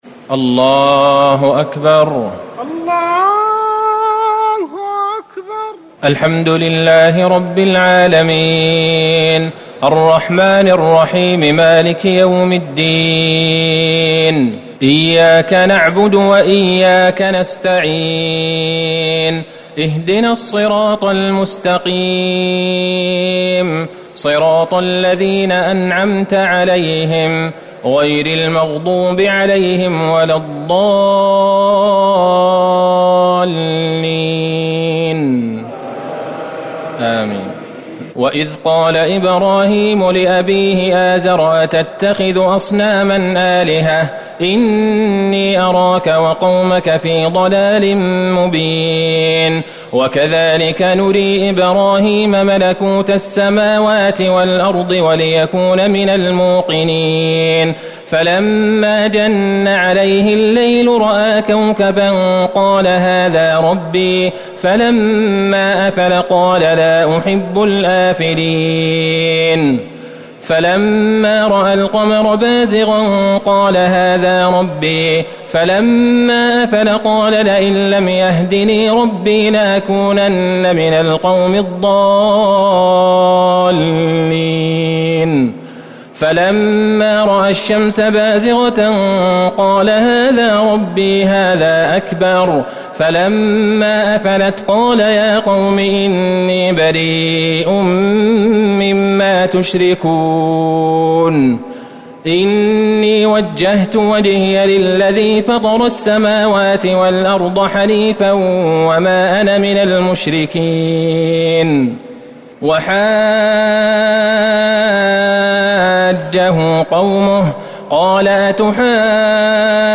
ليلة ٧ رمضان ١٤٤٠ من سورة الانعام ٧٤-١٤٥ > تراويح الحرم النبوي عام 1440 🕌 > التراويح - تلاوات الحرمين